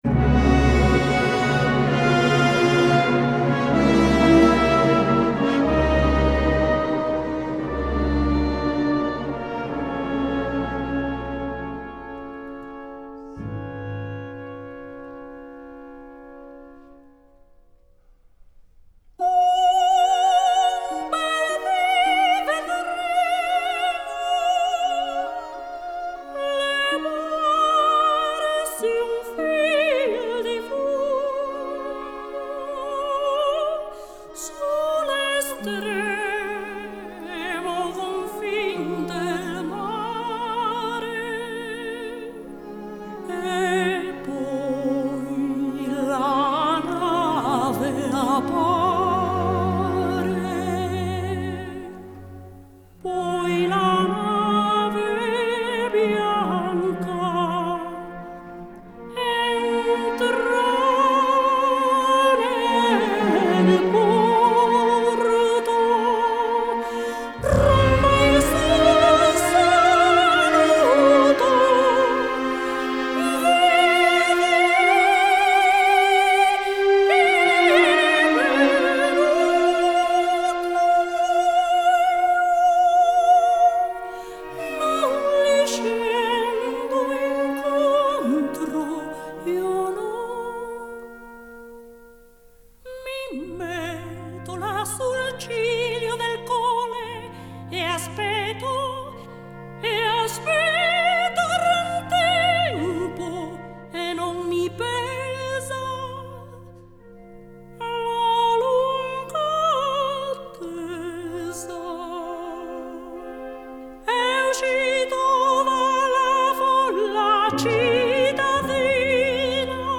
исполнителя популярных оперных арий